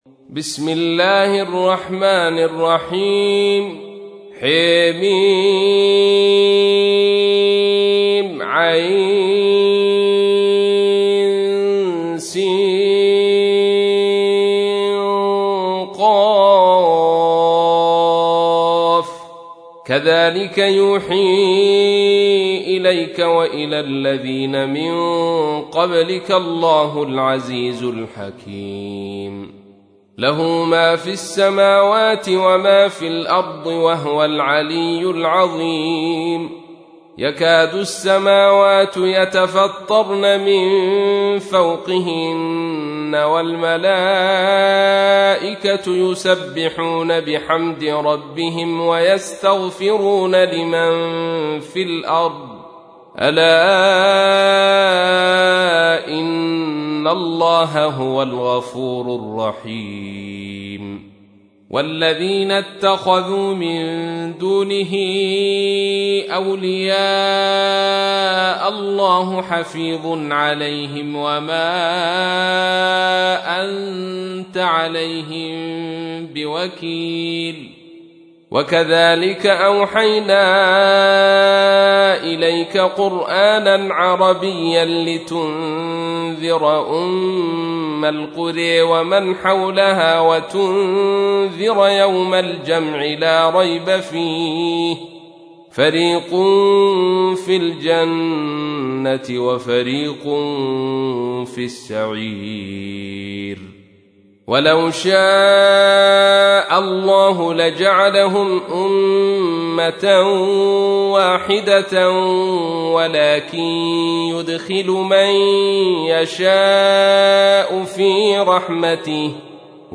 تحميل : 42. سورة الشورى / القارئ عبد الرشيد صوفي / القرآن الكريم / موقع يا حسين